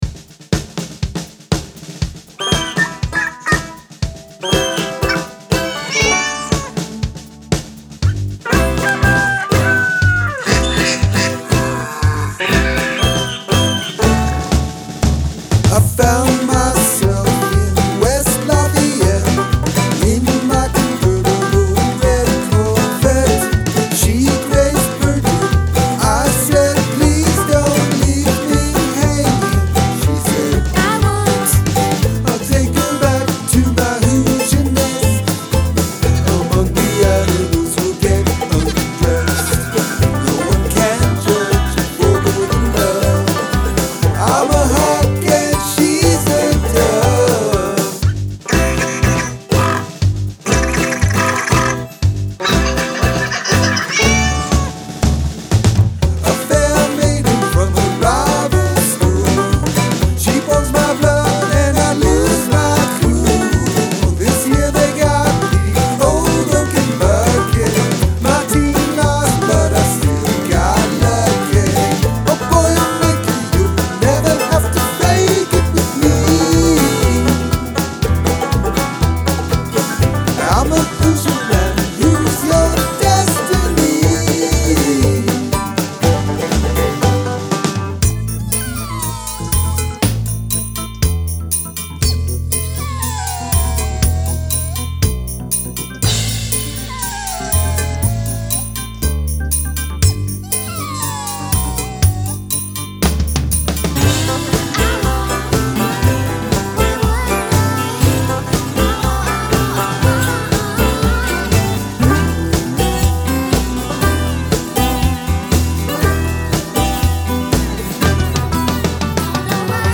Integrate animal sounds into your song's structure